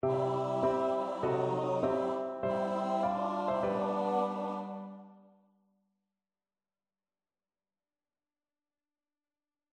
MIDISnaër, François-Michel Samuel, Mass for three voices, Gloria, Allegretto, mm.33-36